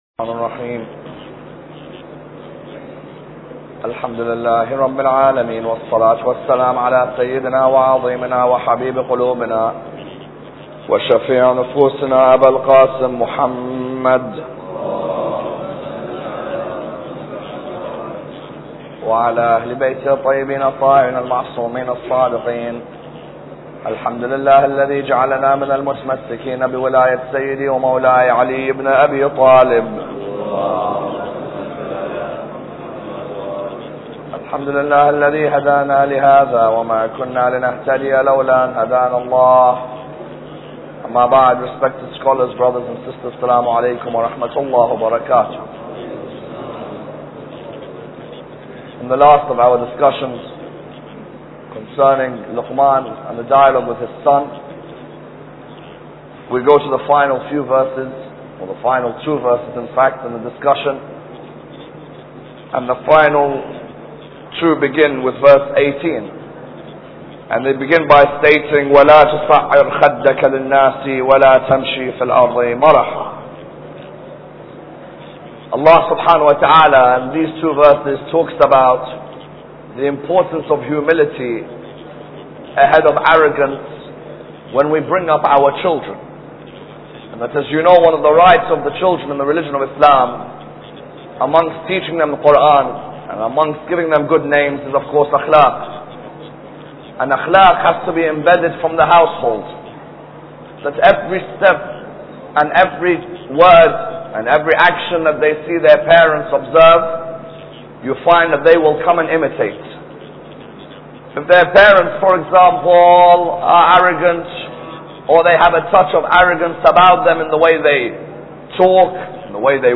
Lecture 13